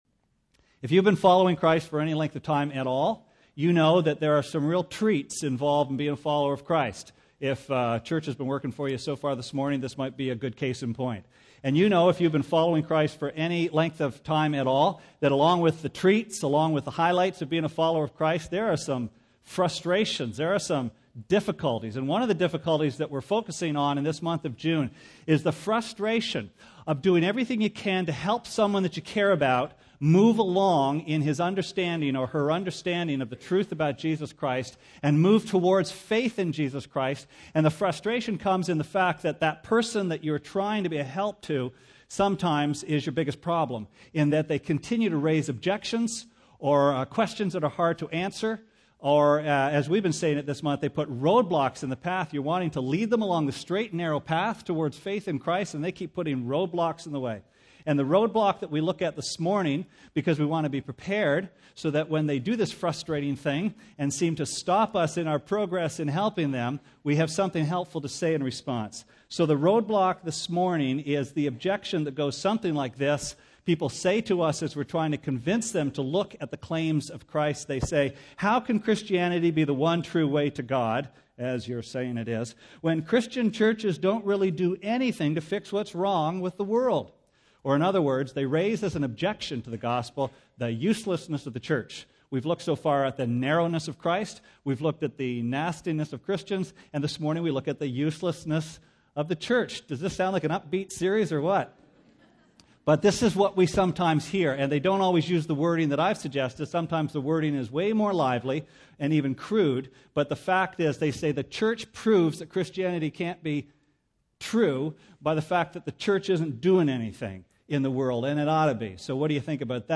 Sermon Archives Jun 22